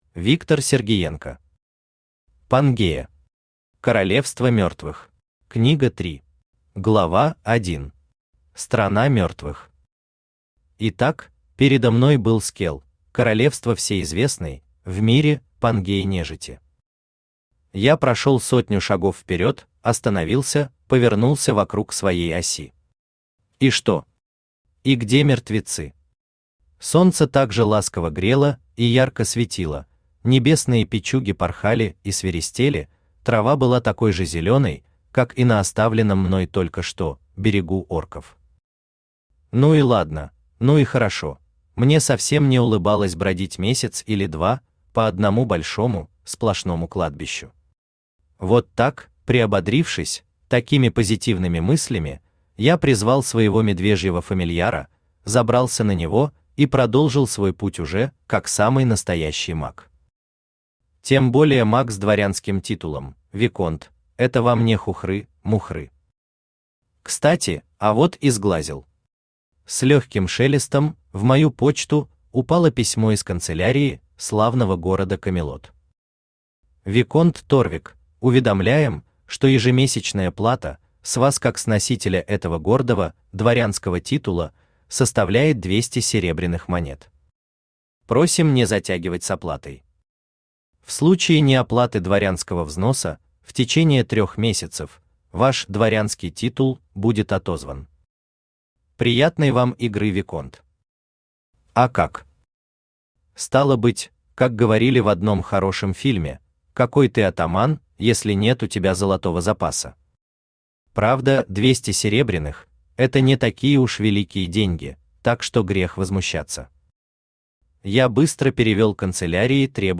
Аудиокнига Пангея. Королевство мертвых. Книга 3 | Библиотека аудиокниг
Книга 3 Автор Виктор Николаевич Сергиенко Читает аудиокнигу Авточтец ЛитРес.